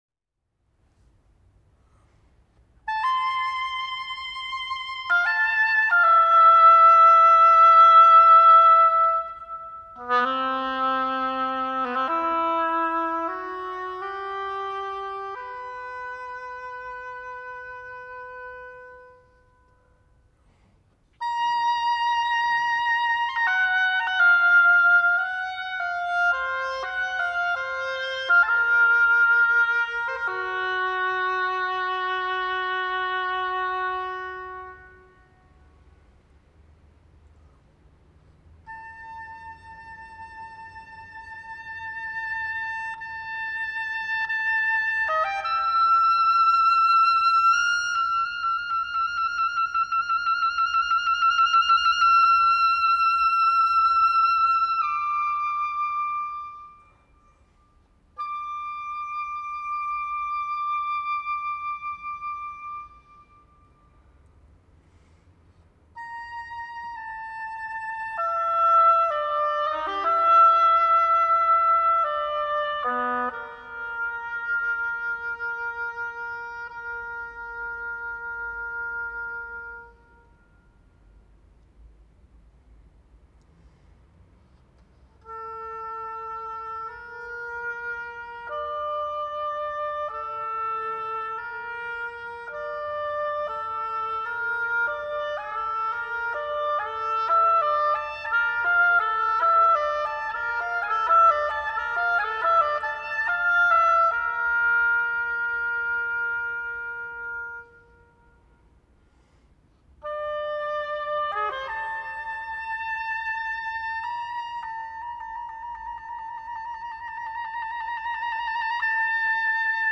Genre solo work
Instrumentation solo oboe
Musical styles and elements melodic, modal
New Music Week Festival 2002
Holmens Kirke, Copenhagen, Denmark